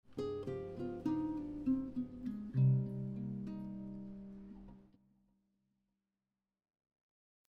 Descending line 2
This descending line starts on the b9 chord tone of the G7 b9 and resolves to the 5th chord tone of C minor 7.